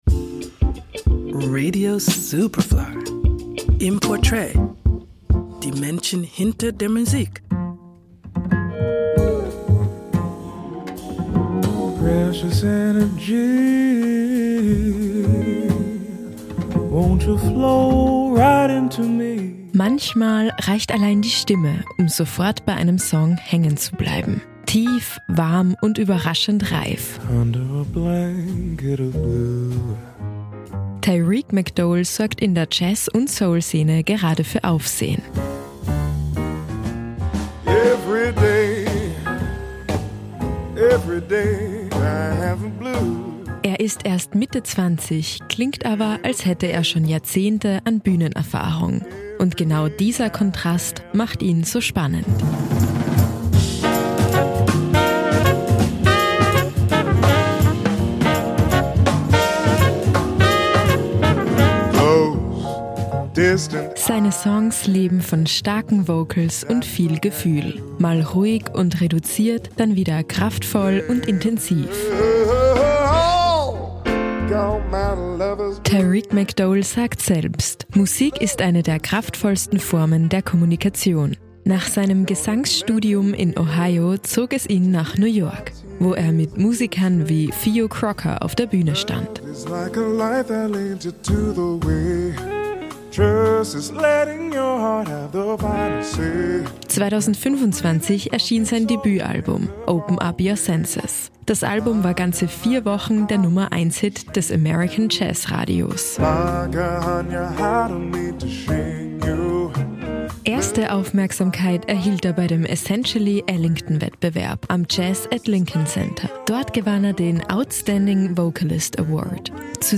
Manchmal reicht die Stimme um sofort bei einem Song hängen zu bleiben – tief, warm und überraschend reif –